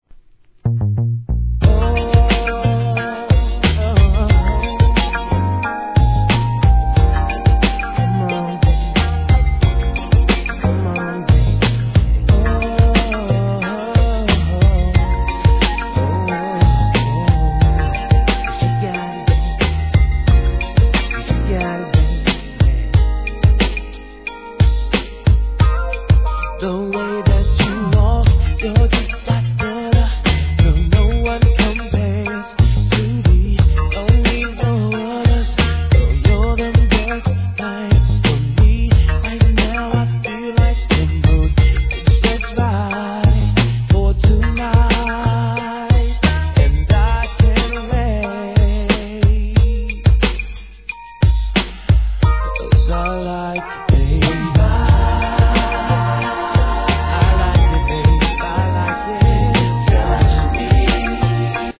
HIP HOP/R&B
'96年、程よいテンポにのせて素晴らしいコーラス・ワークで聴かせる清涼感溢れる爽やかR&B!!